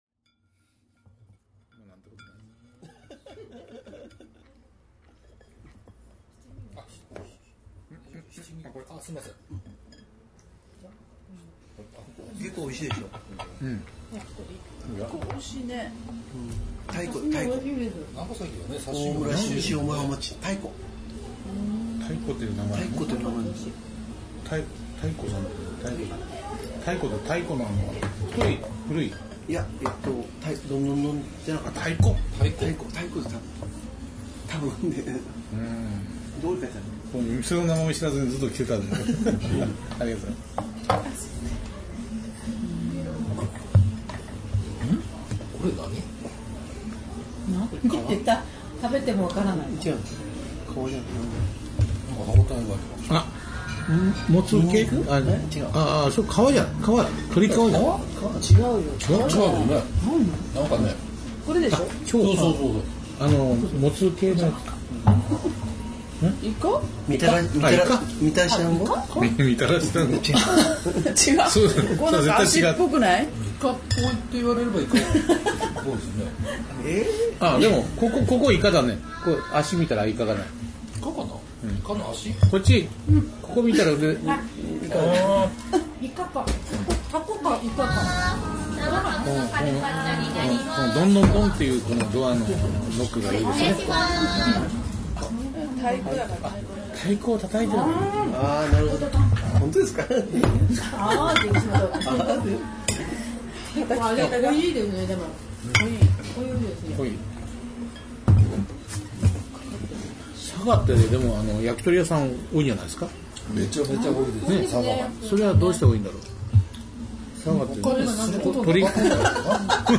前回に引き続き居酒屋よりダラダラとお送りします。